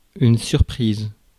Ääntäminen
Ääntäminen France Tuntematon aksentti: IPA: /syʁ.pʁiz/ Haettu sana löytyi näillä lähdekielillä: ranska Käännös Ääninäyte Substantiivit 1. surprise US 2. bombshell 3. start US UK Adjektiivit 4. surprised US Suku: f .